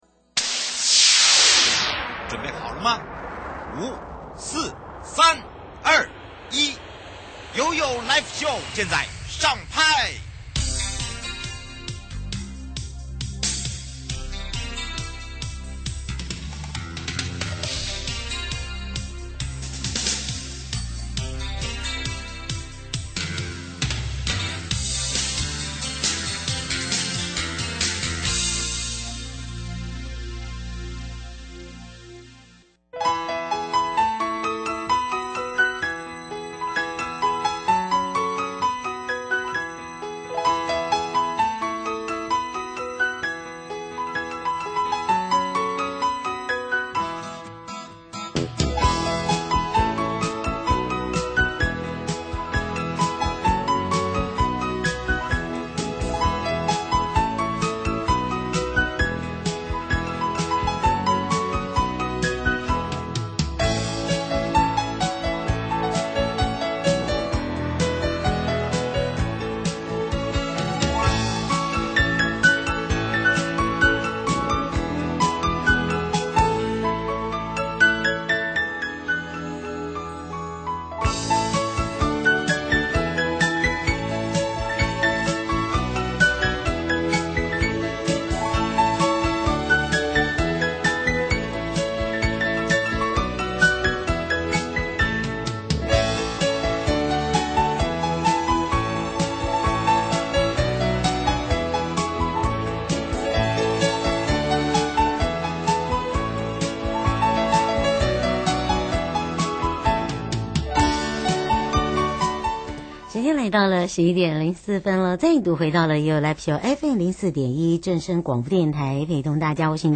受訪者： 1. 台北地檢許祥珍主任檢察官 2. 法務部矯正署黃俊棠署長 節目內容： 1. 校園常見犯罪類型，學生 之間？老師對學生？ 2. 說明精神障礙者，在監說 明與出獄，通報機制